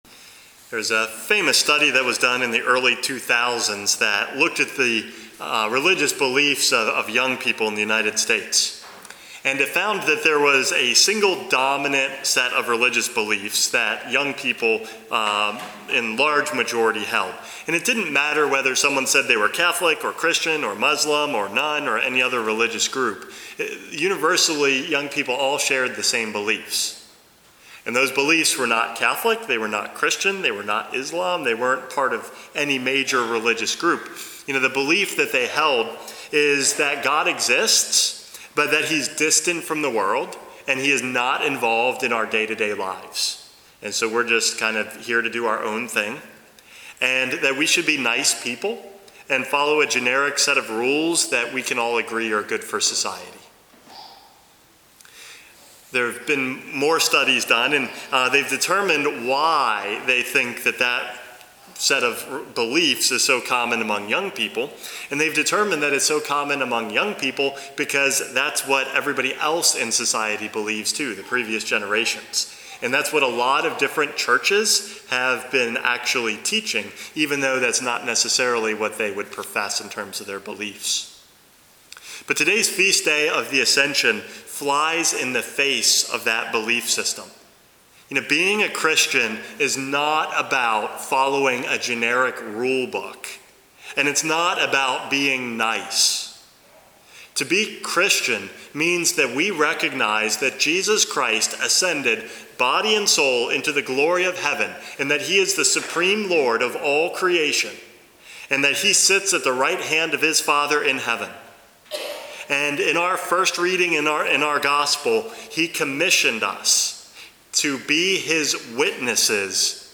Homily #452 - My Witnesses